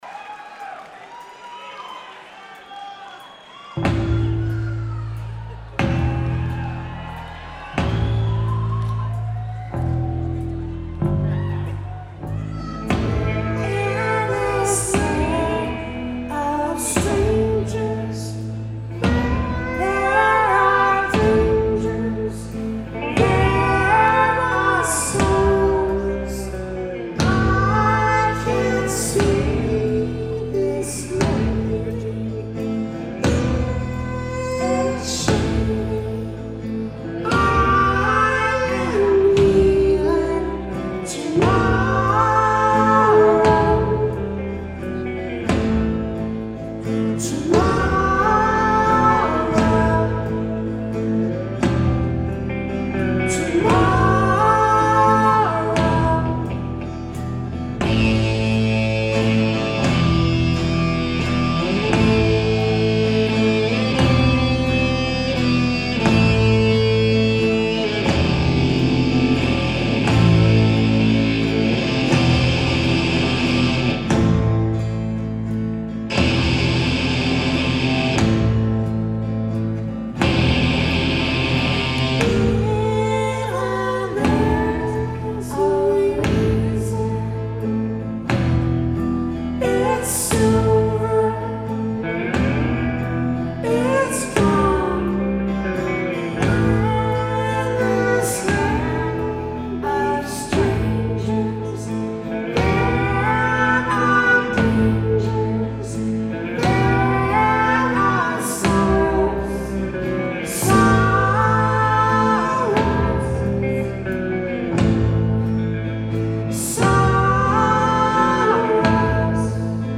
Live at the Wang Theatre / Citi Center
Audience recording
Mics = DPA 4061 > Custom BB > R09HR @ 24/96
Location = Front Row Mezzanine